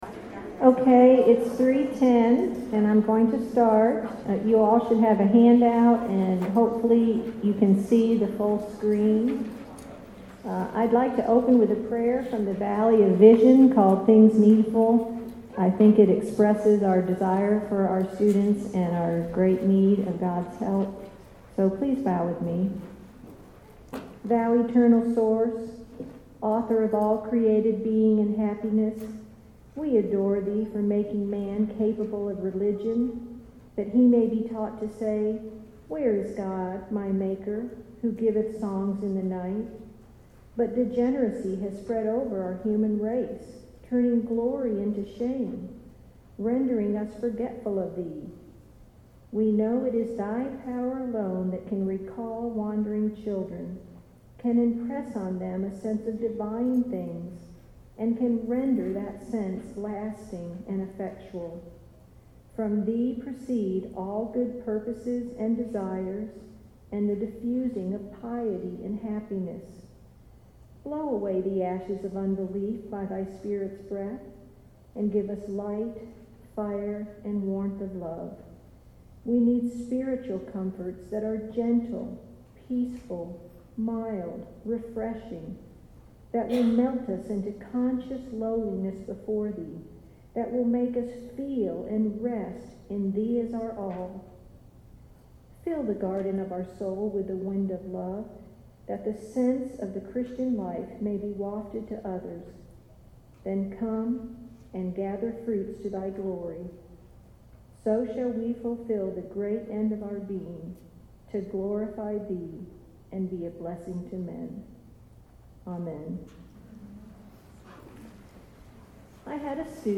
2012 Workshop Talk | 1:03:21 | All Grade Levels, Virtue, Character, Discipline
Speaker Additional Materials The Association of Classical & Christian Schools presents Repairing the Ruins, the ACCS annual conference, copyright ACCS.